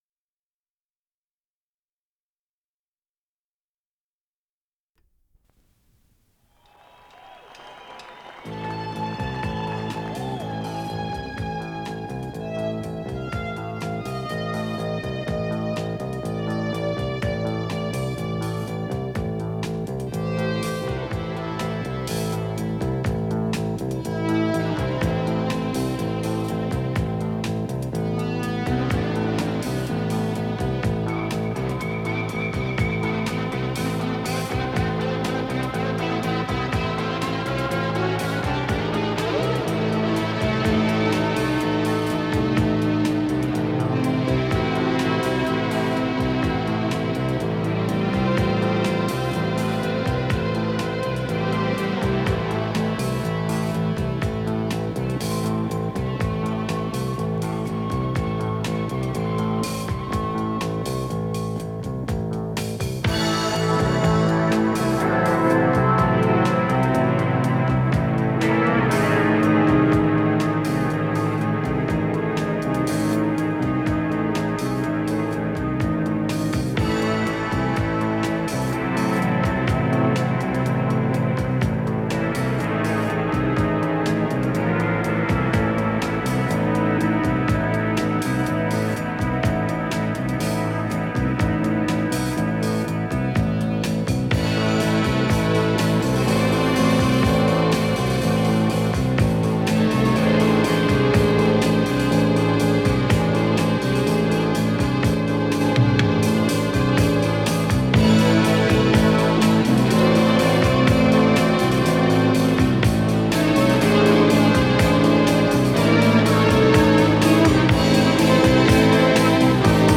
с профессиональной магнитной ленты
Скорость ленты38 см/с
Тип лентыORWO Typ 106